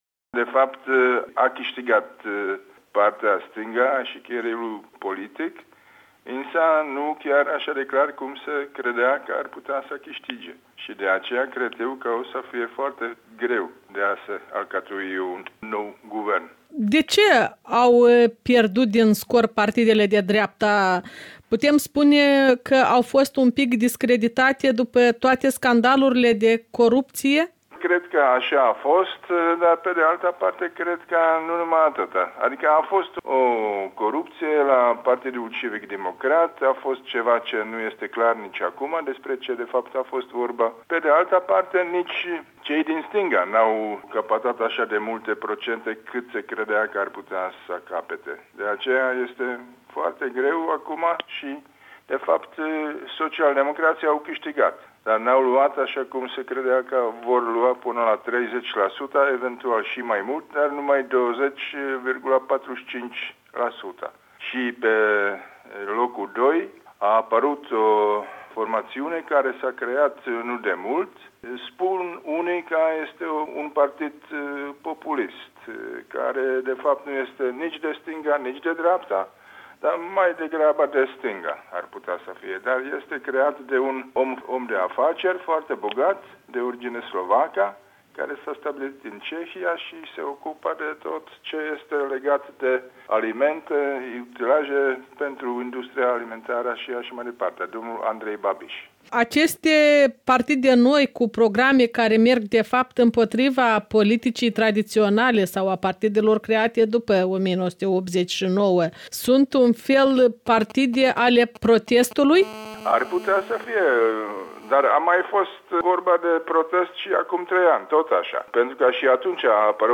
Interviu cu Excelenţa Sa, Ambasadorul Republicii Cehe în Republica Moldova, Jaromír Kvapil